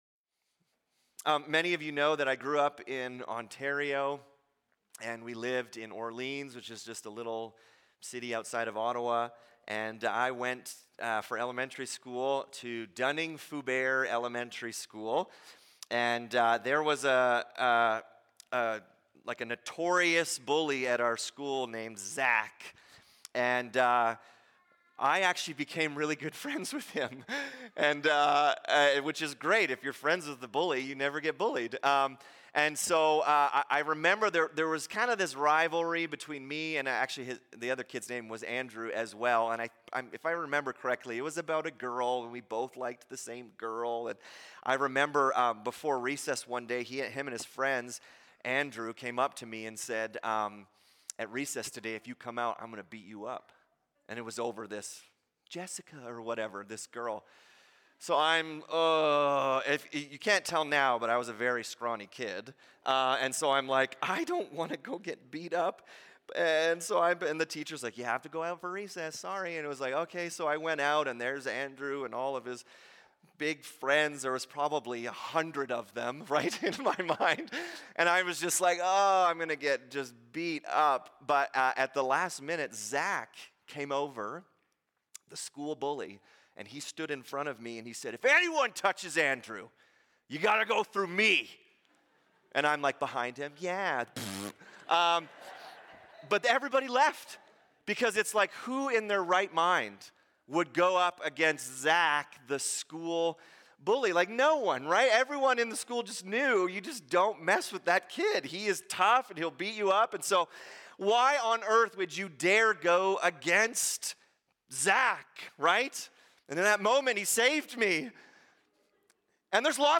Sermons | North Peace MB Church